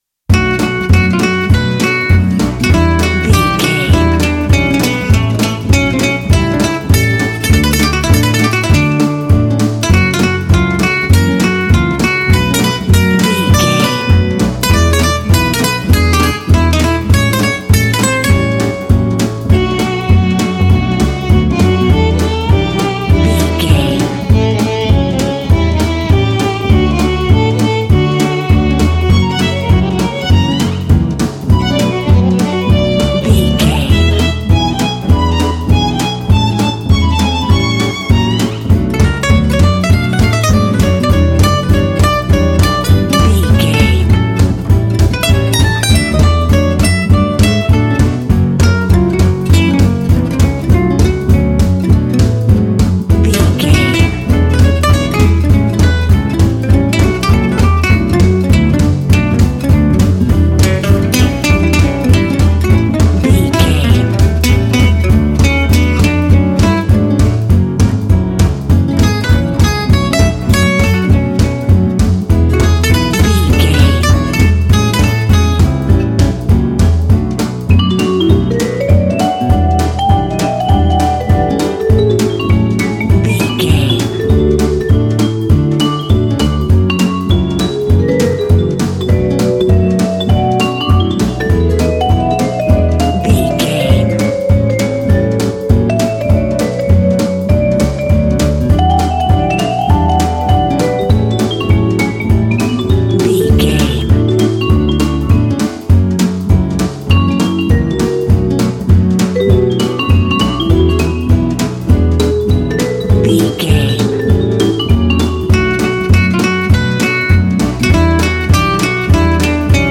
Aeolian/Minor
F♯
Fast